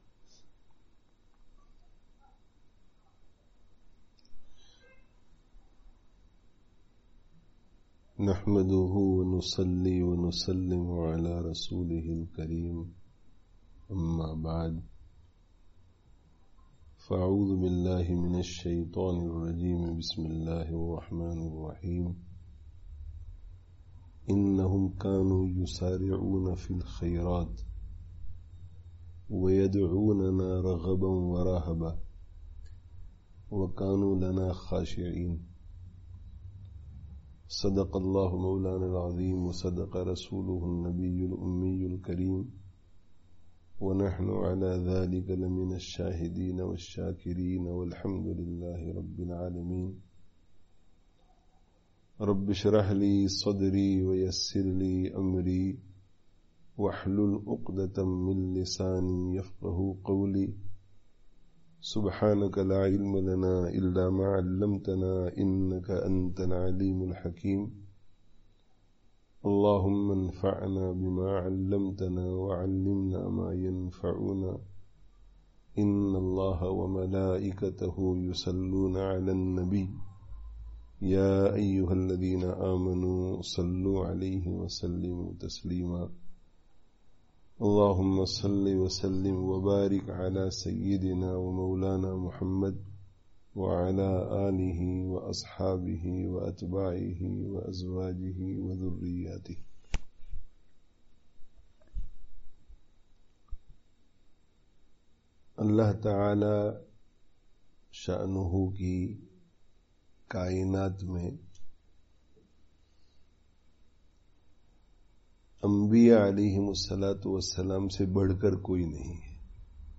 Download friday tazkiyah gathering Urdu 2020 Related articles Allāh ta'ālā kī Farmā(n)bardārī me(n) Kāmyābī hī Kāmyābī hai (14/08/20) Be Shumār Ni'mato(n) ke Bāwajūd Mahrūmī kā Ihsās?